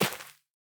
Minecraft Version Minecraft Version snapshot Latest Release | Latest Snapshot snapshot / assets / minecraft / sounds / block / rooted_dirt / break2.ogg Compare With Compare With Latest Release | Latest Snapshot